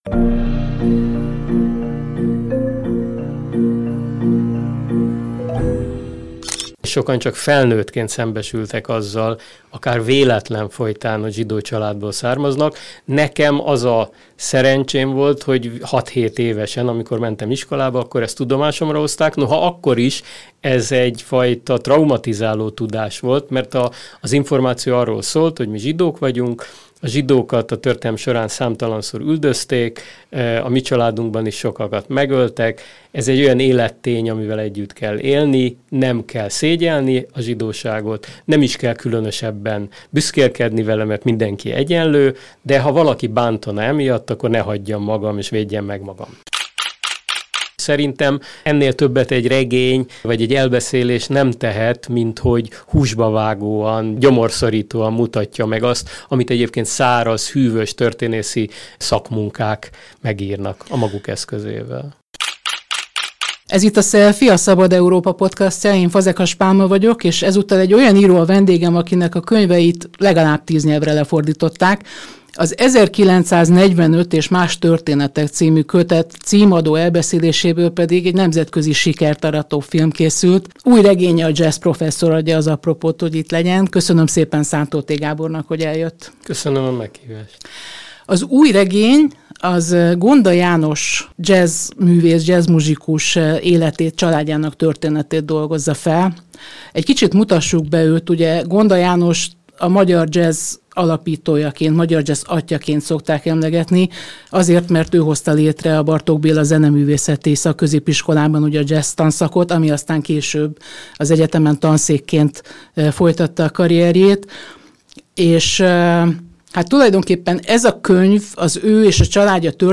Az interjúban szó lesz zsidó identitásról, öröklődő traumákról és arról, hogyan teheti az irodalom érthetőbbé a múltat, elviselhetőbbé a következményeit.